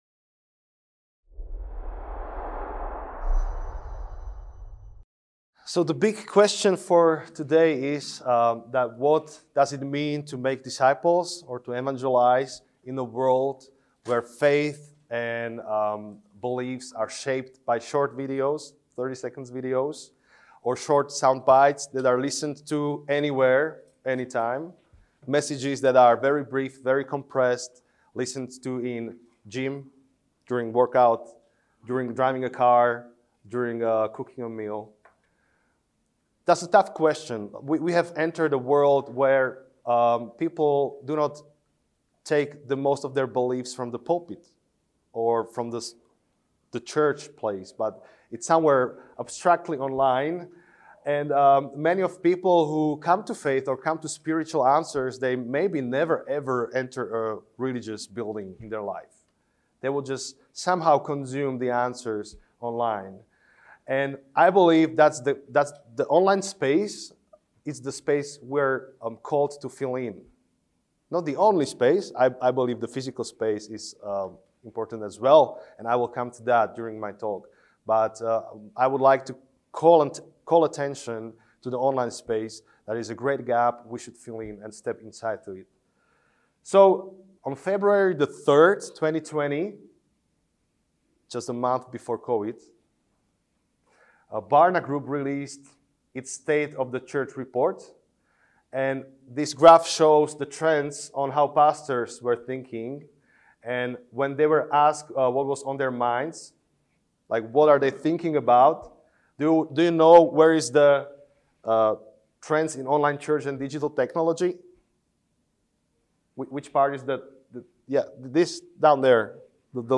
The world has moved from analog to digital, from offline to online, from local to global. In this talk, we outline a few ways in which we can leverage these fundamental shifts in technology for sharing the Gospel.